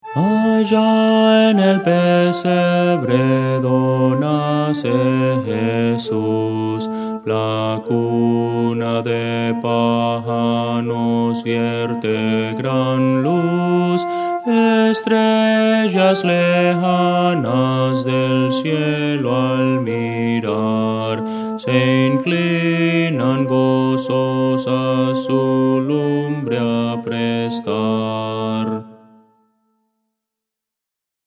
Voces para coro
Contralto – Descargar